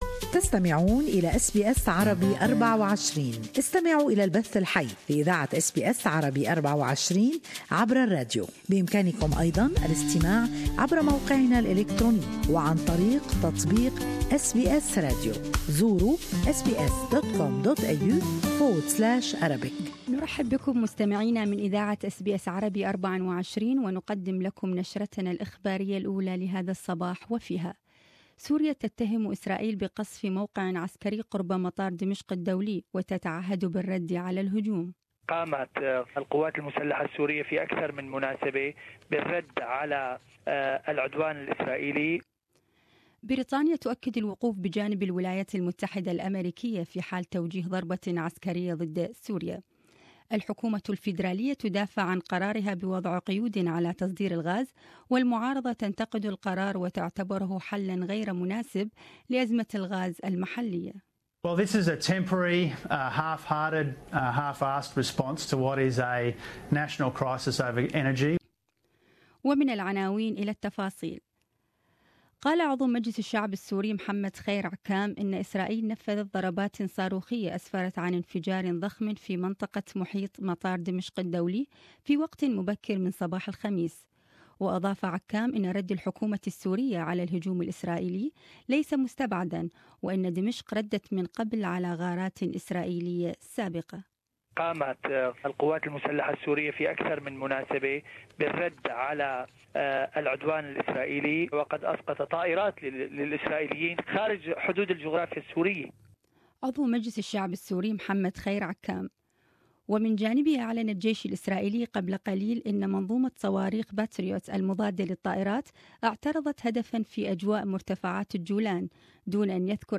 News Bulletin: Domestic users pay more for Australian gas than Japanese